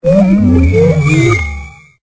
Cri_0518_EB.ogg